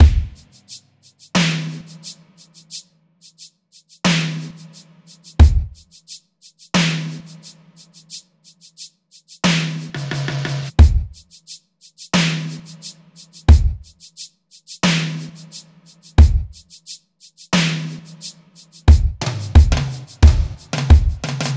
03 drums B.wav